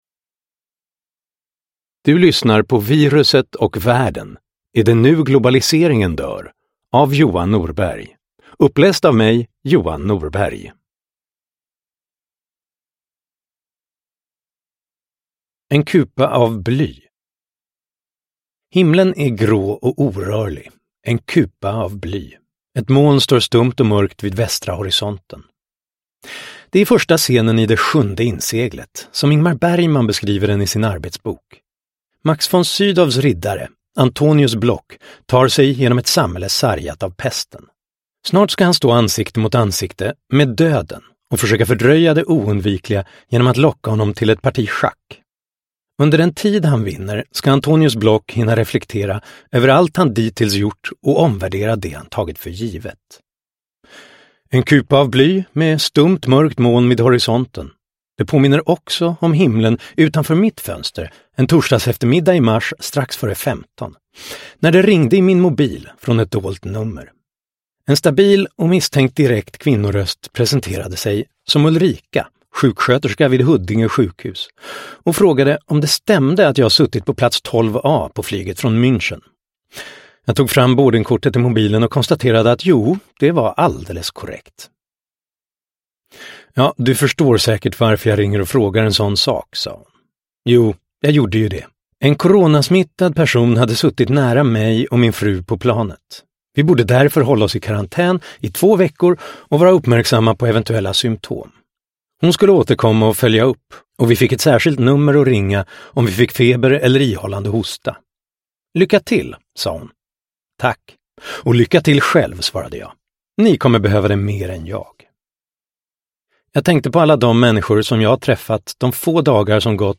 Viruset och världen : Är det nu globaliseringen dör? – Ljudbok – Laddas ner